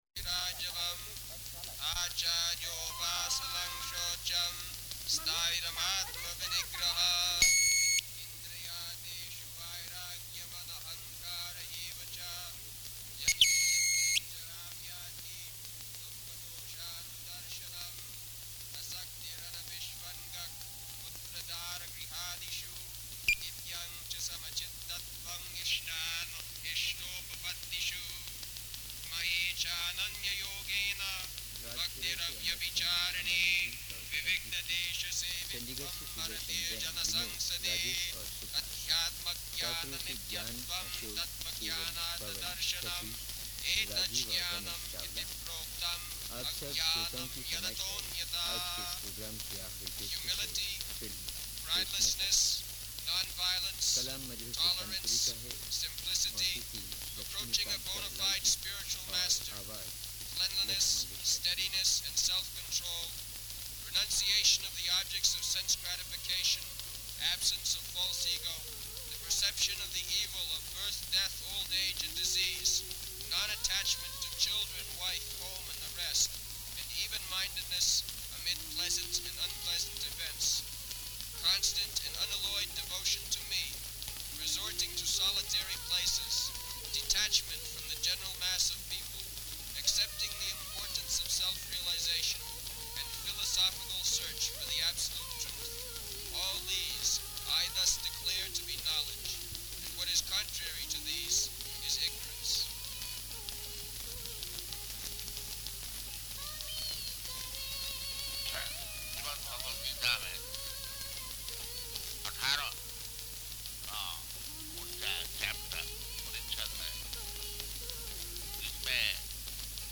Location: Chandigarh